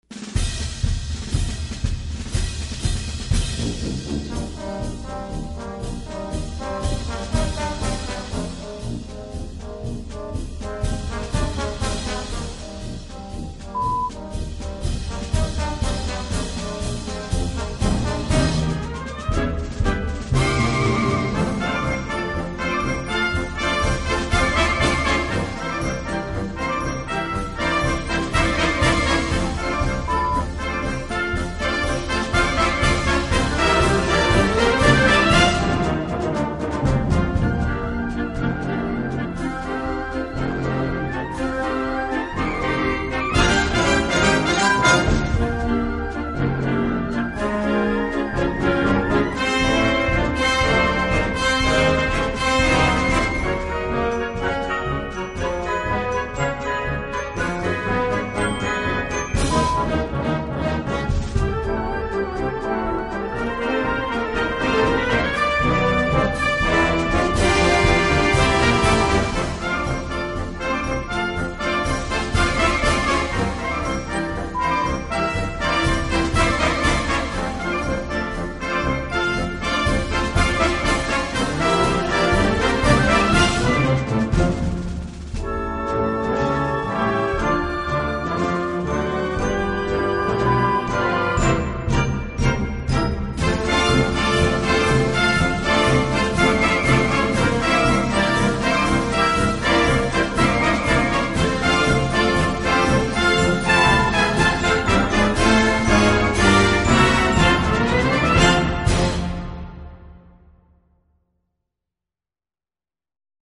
Gattung: Marsch-Thema aus dem Musical
Besetzung: Blasorchester